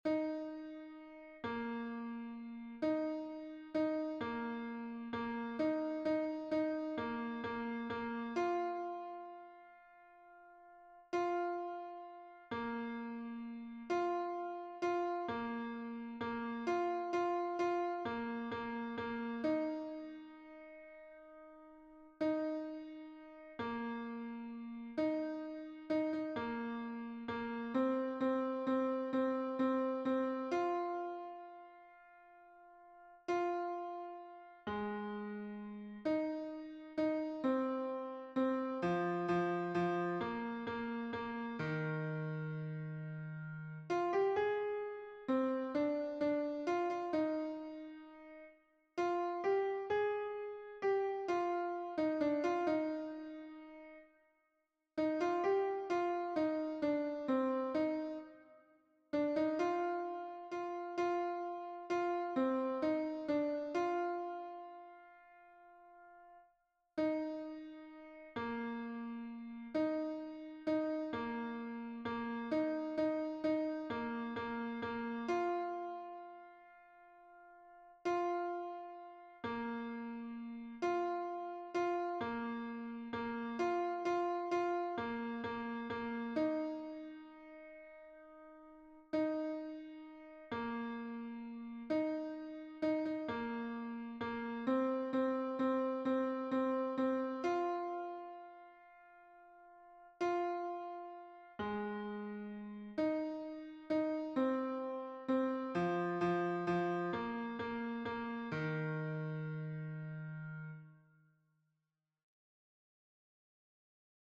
Mp3 version piano
Basse